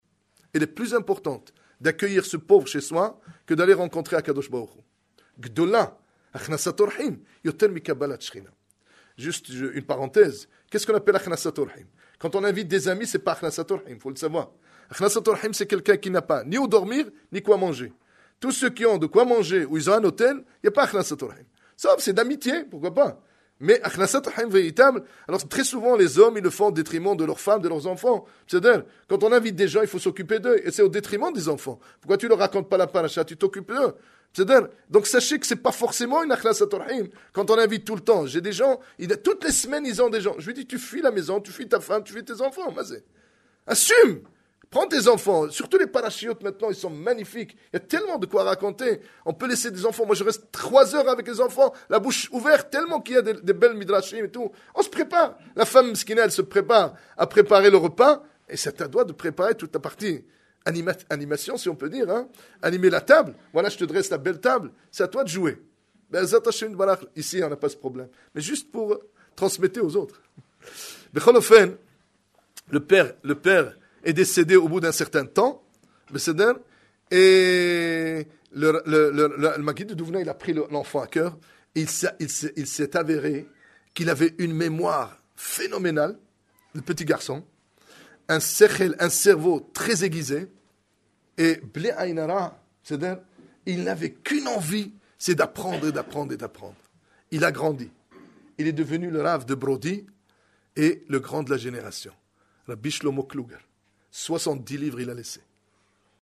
Cet exposé donné au Igoud ‘Harédi à Paris le Motsé Shabbath Shémoth 20 Téveth 5778 – 06 janvier 2018